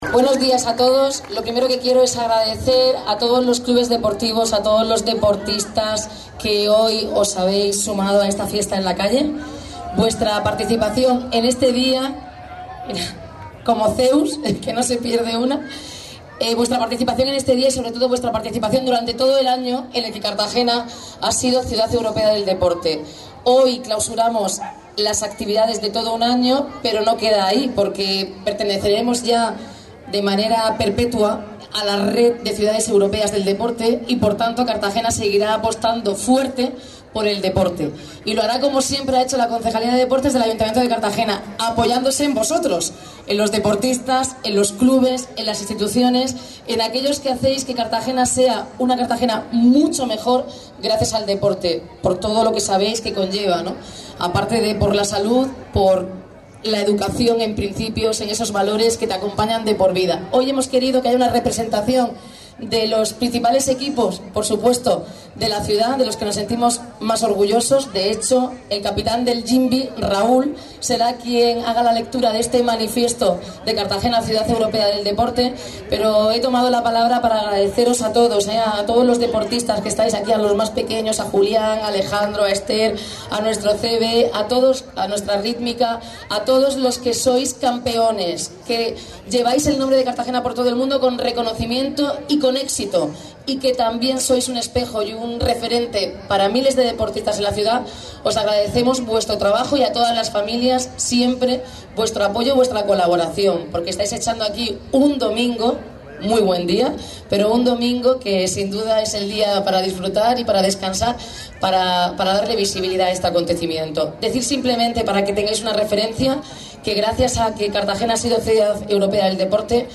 Enlace a Declaraciones de Noelia Arroyo en la clausura de Ciudad Europea del Deporte